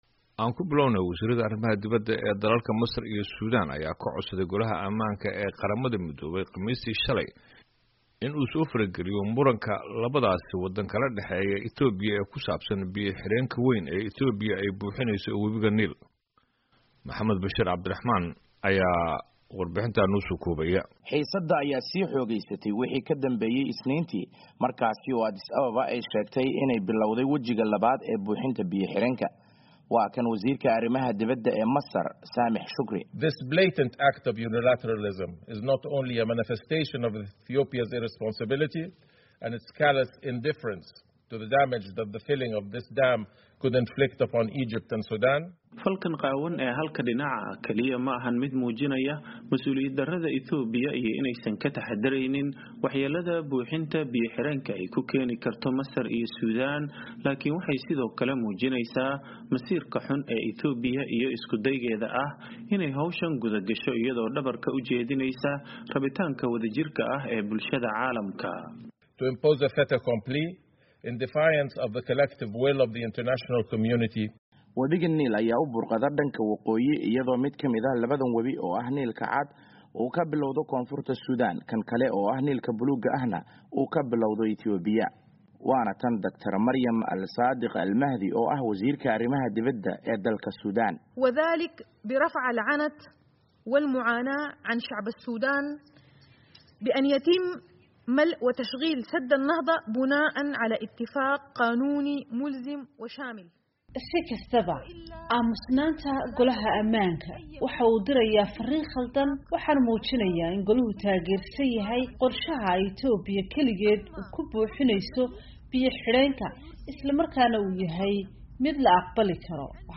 warbixintan inoo soo koobaya.